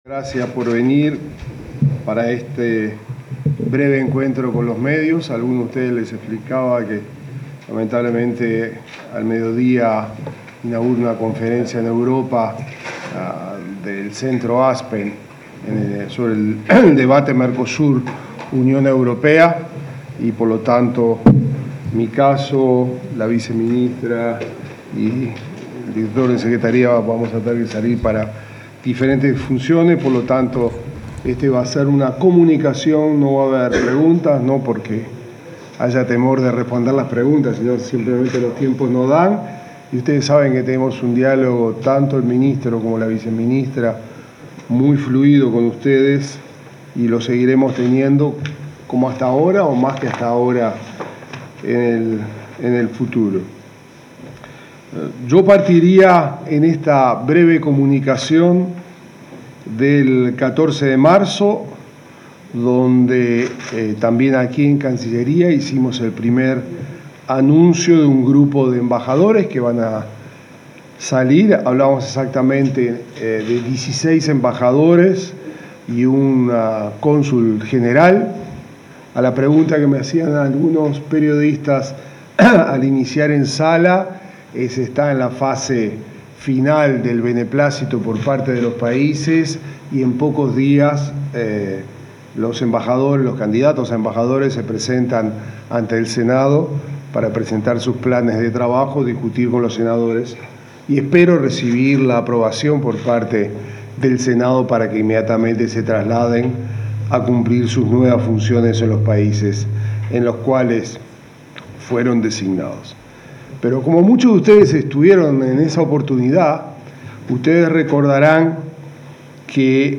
Palabras del ministro de Relaciones Exteriores, Mario Lubetkin